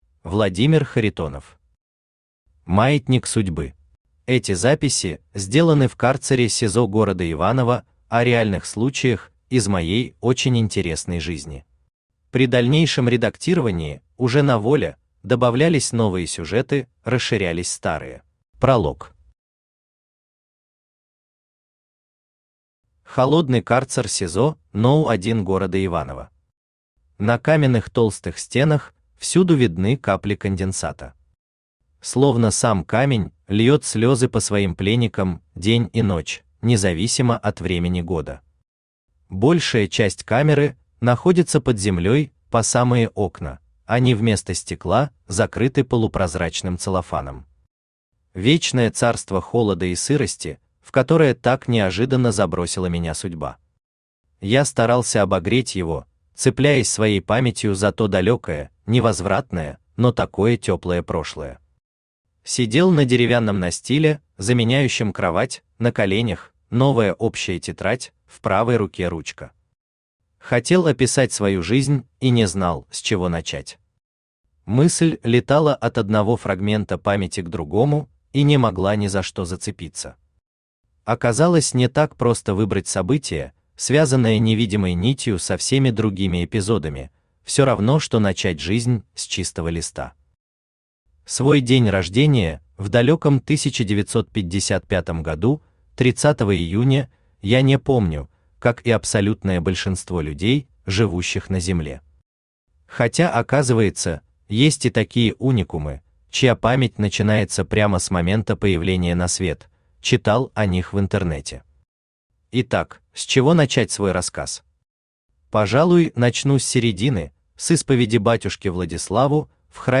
Аудиокнига Маятник Судьбы | Библиотека аудиокниг
Aудиокнига Маятник Судьбы Автор Владимир Юрьевич Харитонов Читает аудиокнигу Авточтец ЛитРес.